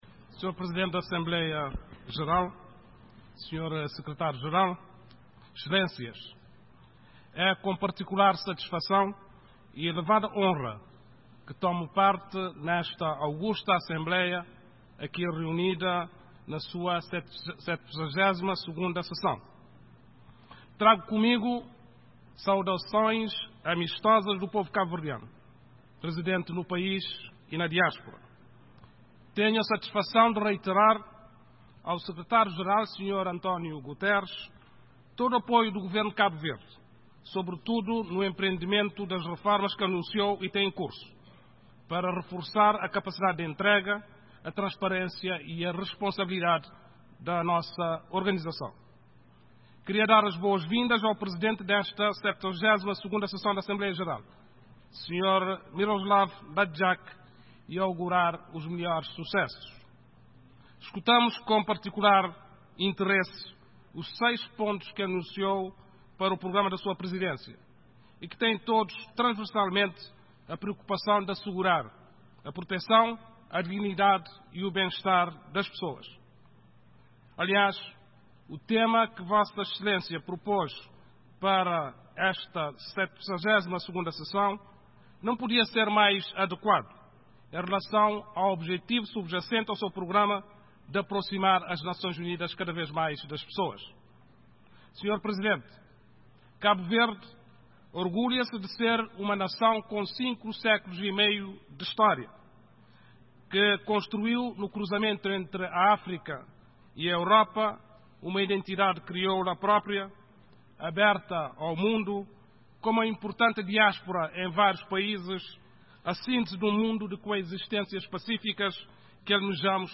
Discurso do primeiro-ministro de Cabo Verde nas Nações Unidas - 16:00